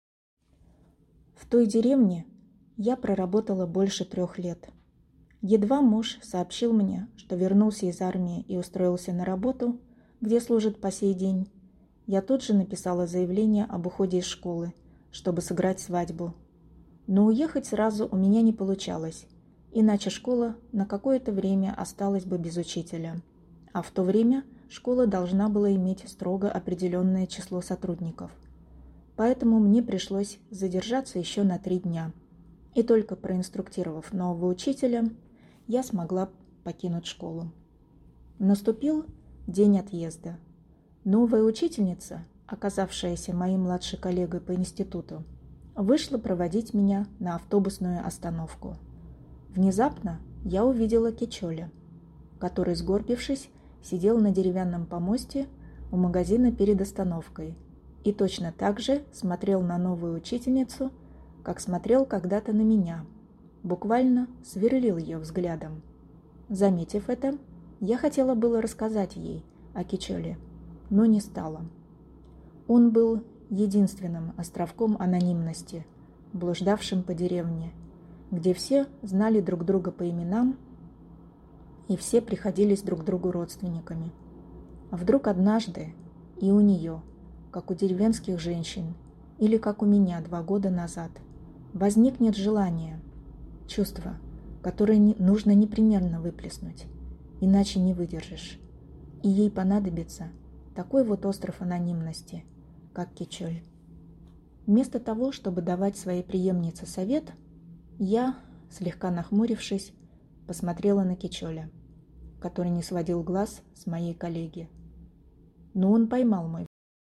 Каждый переводчик читает свой текст; вы услышите 11 голосов и 11 неповторимых творческих интонаций.